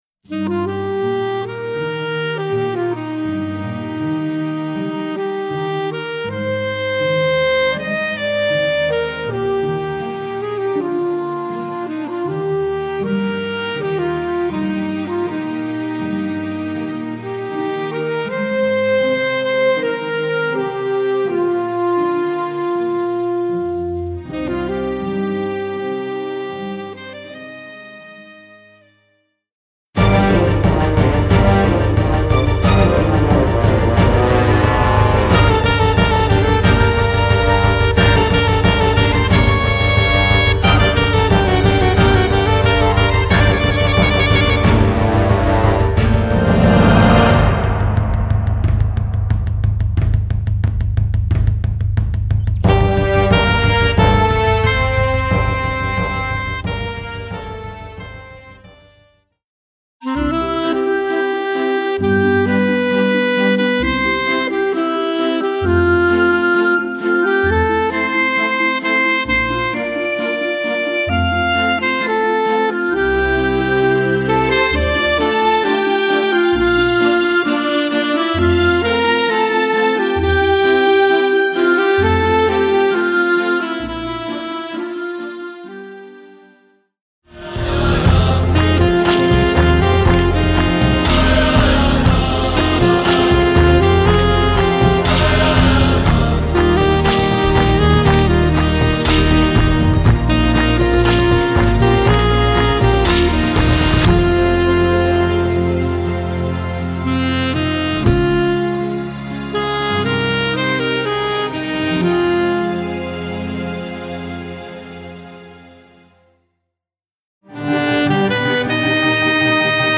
Voicing: Alto Saxophone w/ Audio